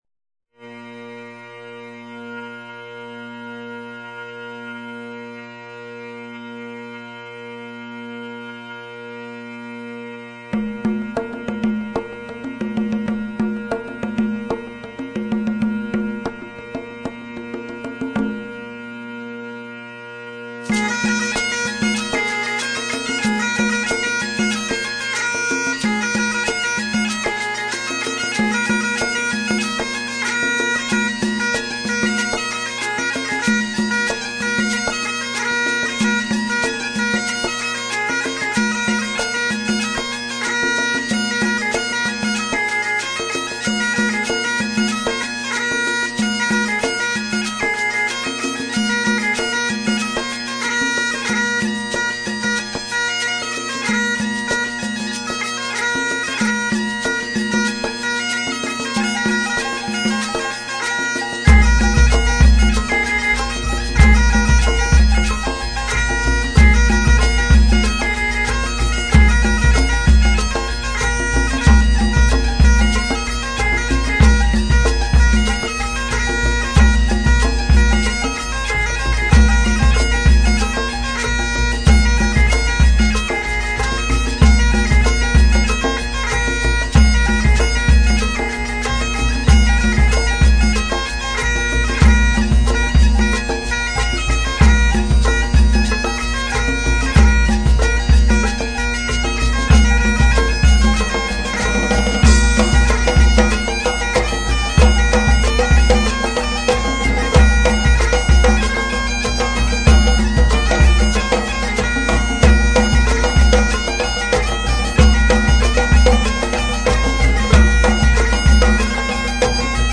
这支乐团，融合了高地风笛的热情和原始部落的韵律，以诙谐的风格再现了一百多年前苏格兰婚礼、同乐会或高地狩猎营火会的音乐。